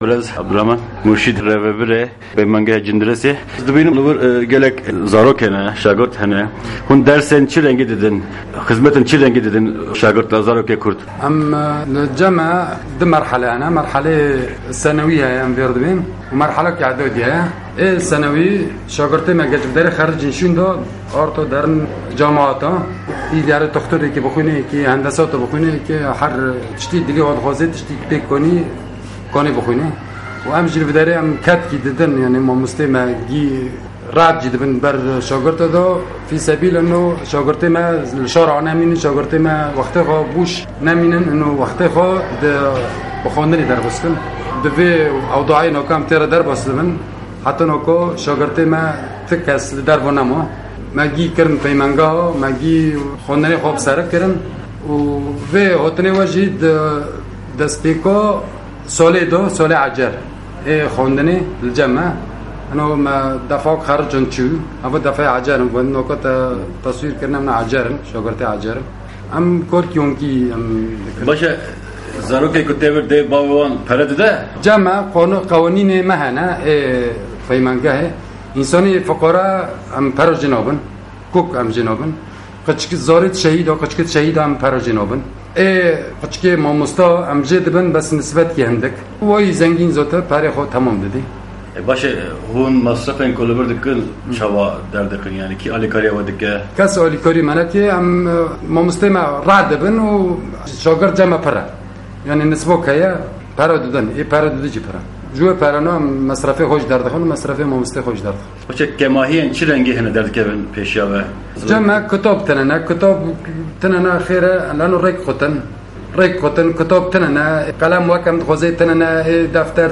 Hevpeyvîneke Taybet ya Ser Xwendina Zarokan li Cîndirêsa Efrînê